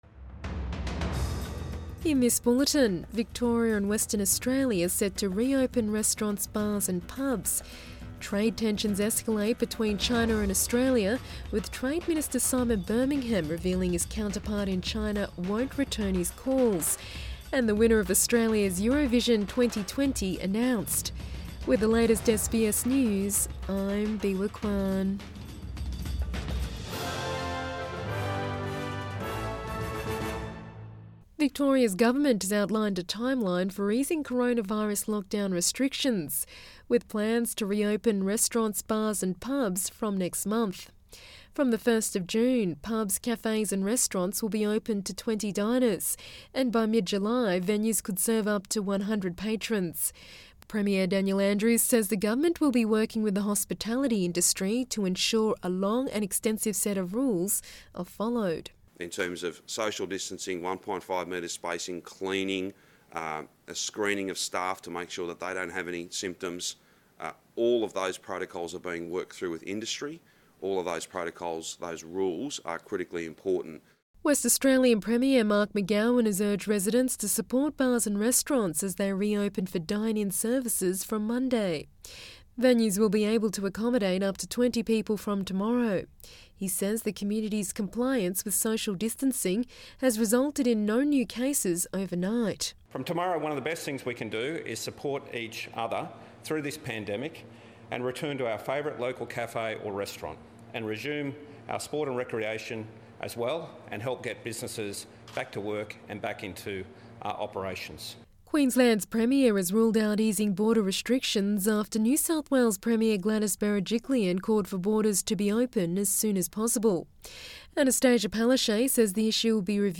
PM bulletin 17 May 2020